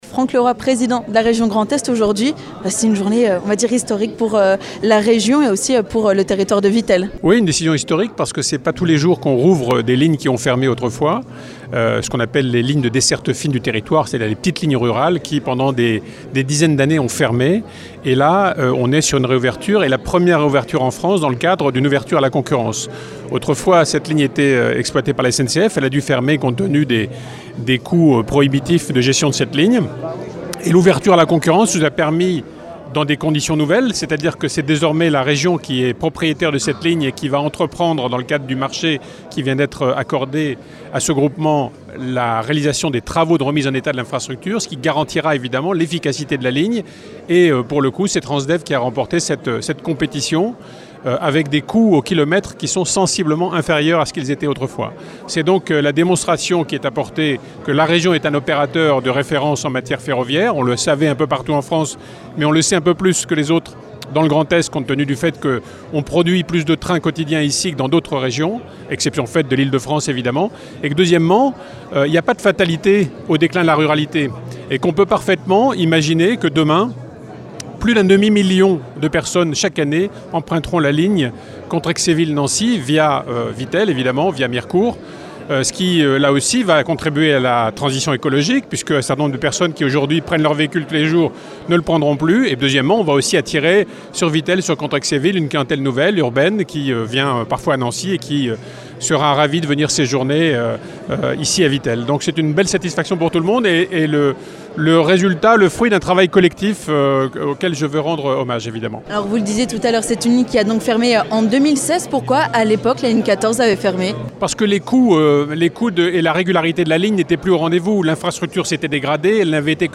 Franck Leroy, président de la Région Grand Est, nous en dit plus sur la réouverture de la Ligne 14 !